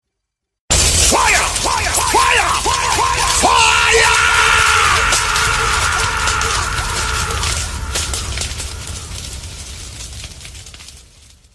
Play, download and share DJ WHEEL UP FIRE original sound button!!!!
2019-lattest-pull-it-up-effects-1-audiotrimmer.mp3